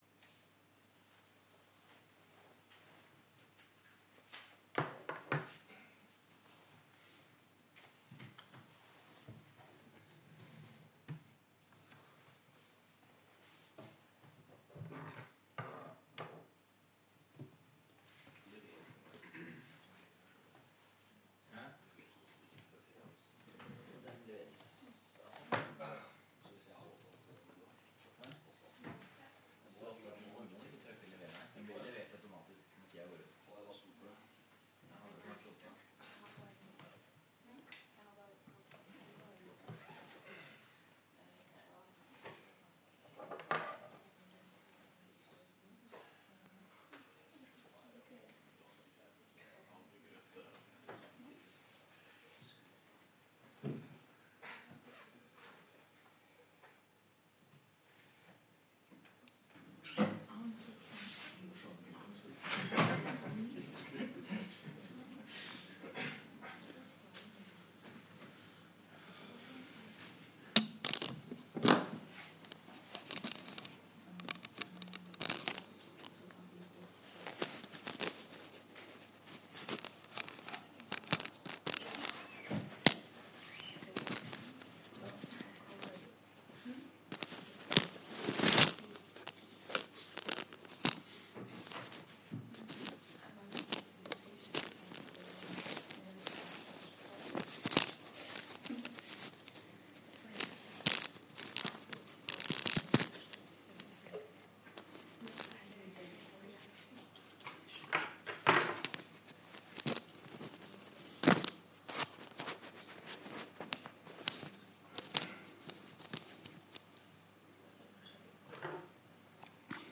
- NTNU Forelesninger på nett